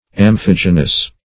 Search Result for " amphigenous" : The Collaborative International Dictionary of English v.0.48: Amphigenous \Am*phig"e*nous\, a. (Bot.)
amphigenous.mp3